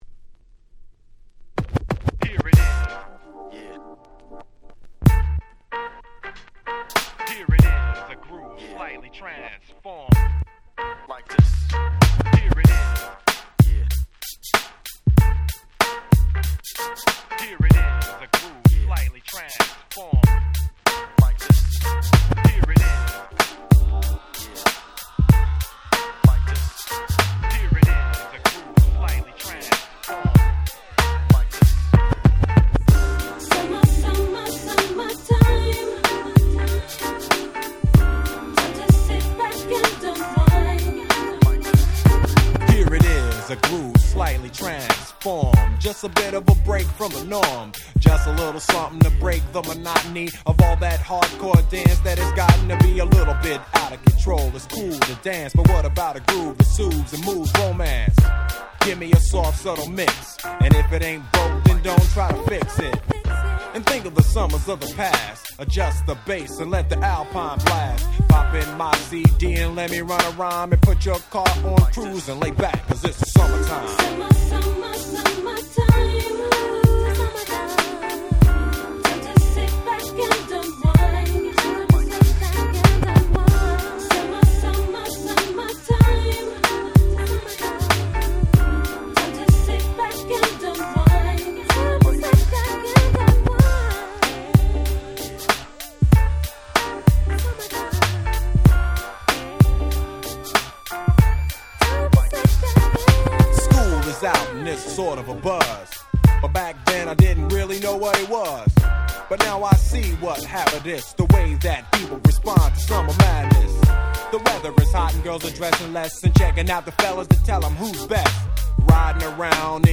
98' Smash Hit Hip Hop !!